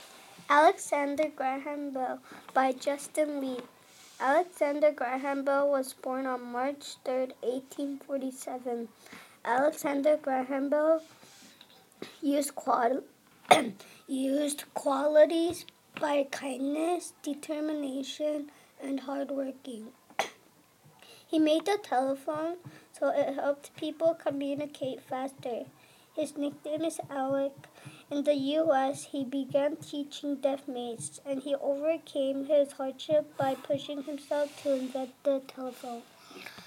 Bell